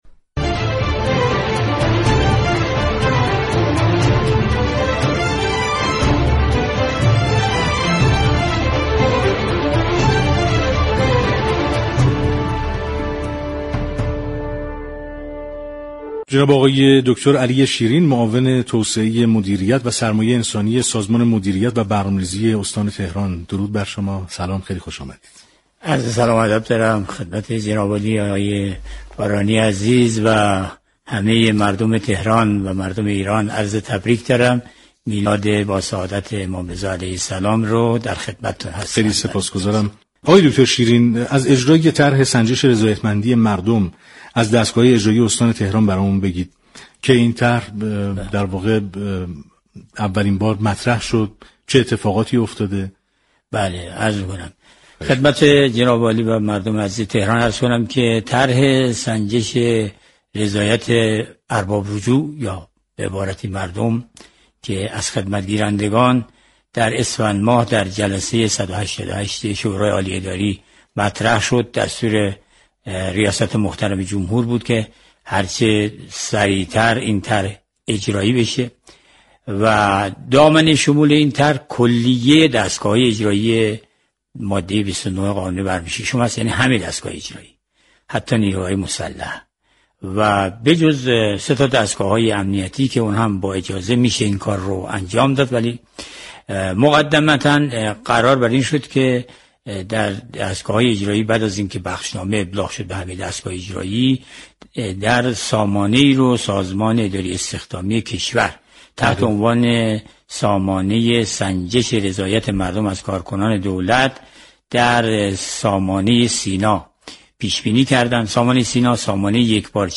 به گزارش پایگاه اطلاع رسانی رادیو تهران، علی شیرین معاون توسعه مدیریت و سرمایه انسانی سازمان مدیریت و برنامه‌ریزی استان تهران با حضور در استودیو پخش زنده رادیو تهران در هشتمین روز از خرداد سال جاری با برنامه «پل مدیریت» گفت و گو كرد.